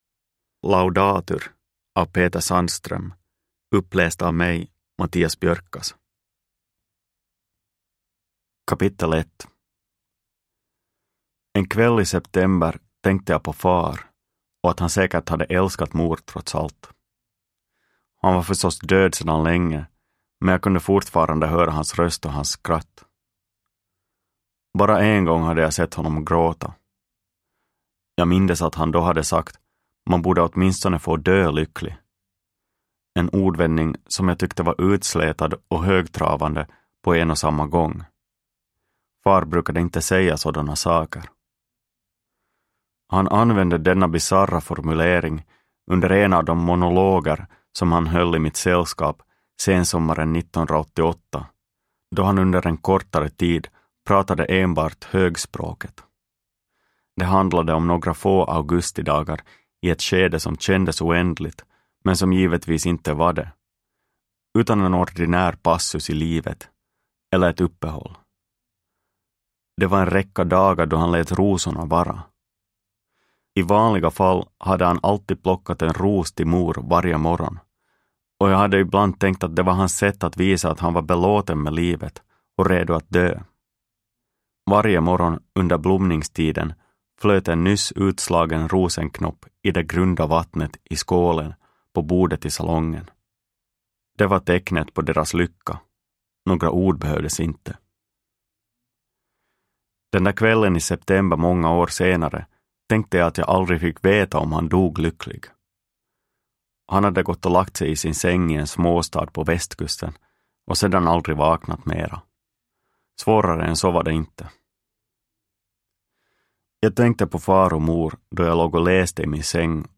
Laudatur – Ljudbok – Laddas ner